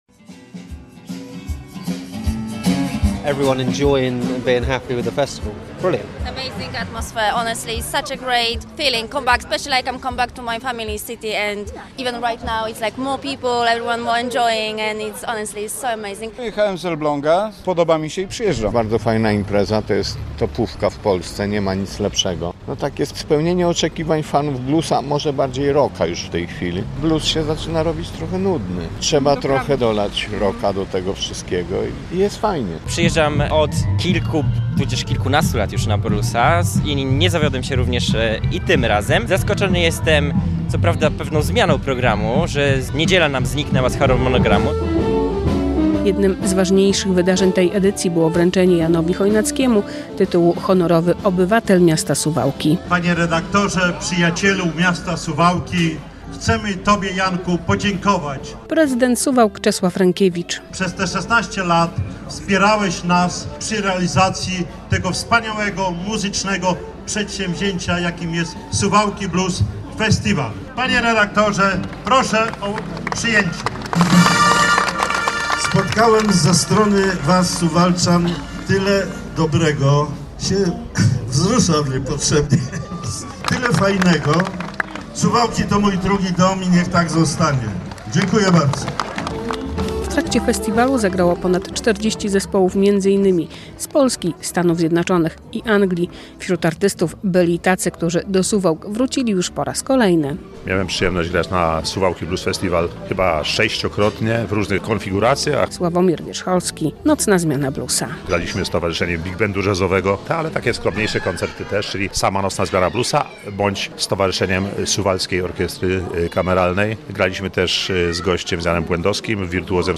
Zakończył się 16. Suwałki Blues Festival - relacja